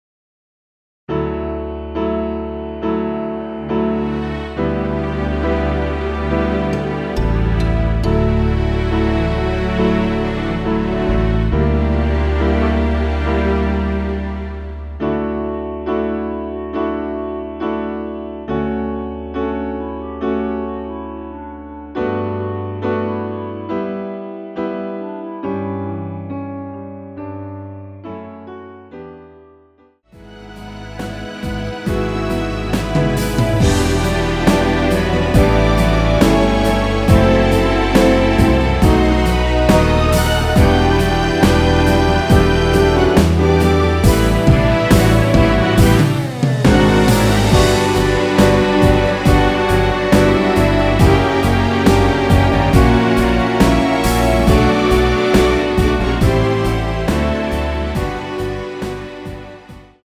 원키에서(-2)내린 멜로디 포함된 MR입니다.
D
앞부분30초, 뒷부분30초씩 편집해서 올려 드리고 있습니다.
중간에 음이 끈어지고 다시 나오는 이유는